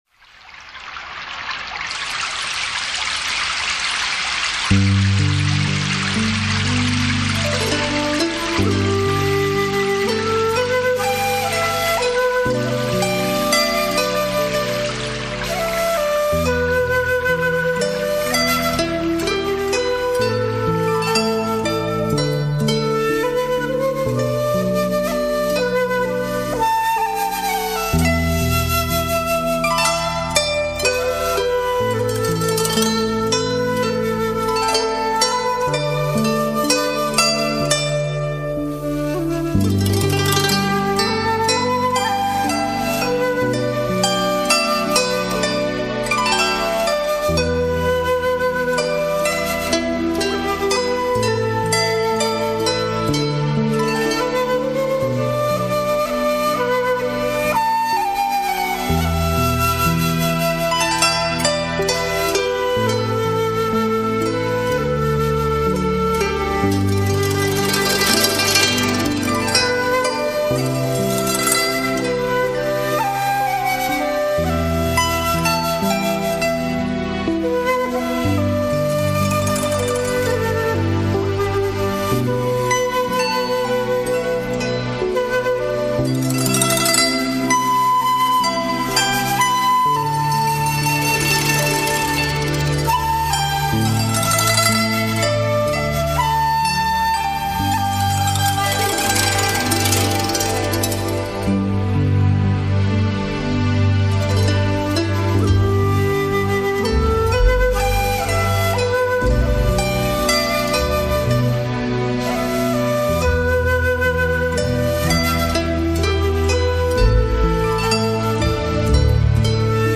乐声或欢快活泼、或神秘浪漫、或精致优雅、或古朴凝重……